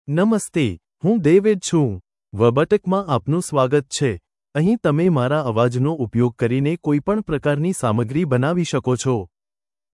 MaleGujarati (India)
DavidMale Gujarati AI voice
Voice sample
Male
David delivers clear pronunciation with authentic India Gujarati intonation, making your content sound professionally produced.